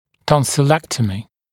[ˌtɔnsɪˈlektəmɪ][ˌтонсиˈлэктэми]тонзиллэктомия, удаление миндалин
tonsillectomy.mp3